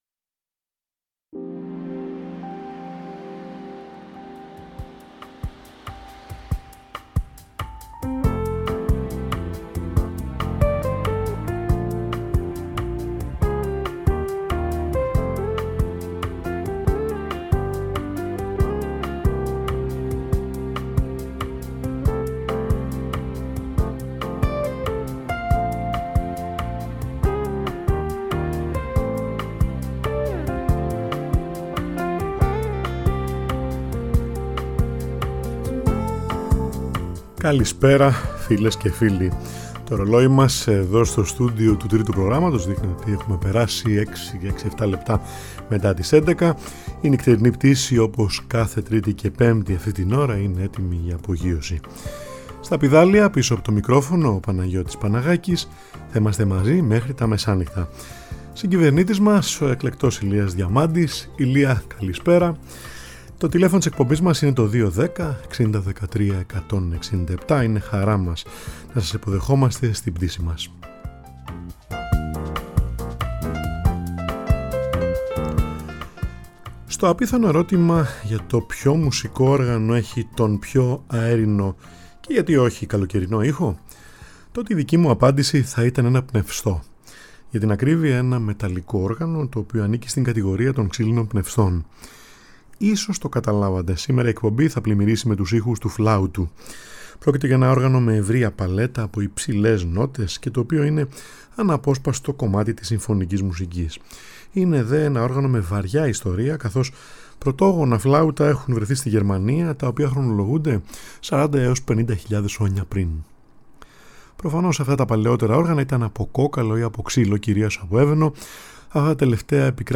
Ο ήχος του φλάουτου σαν καλοκαιρινό αεράκι: σπουδαίες και σπουδαίοι σολίστ των ημερών μας σε έργα των Poulenc, Franck, Mozart, Takemitsu, Fagerlund, Mendelssohn, Chopin, Piazzolla, Drummond, Faure, Villa-Lobos.